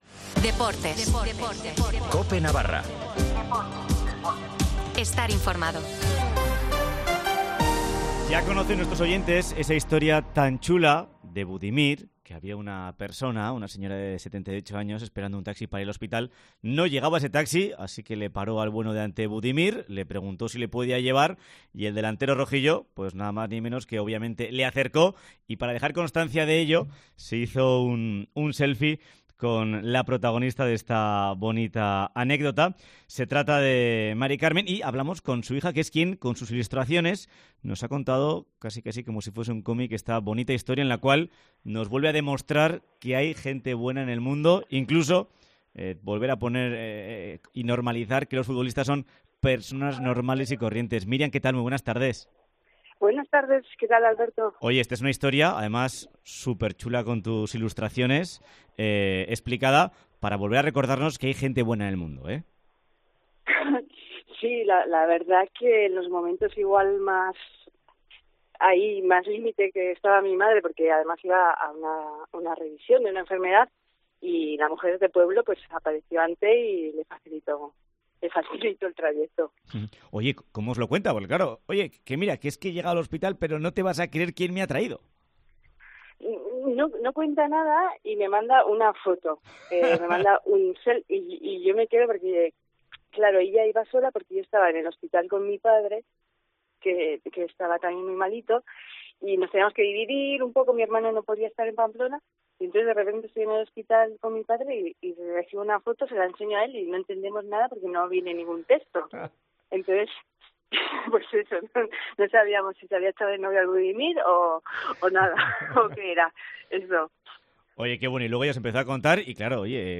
ENTREVISTA CON SU HIJA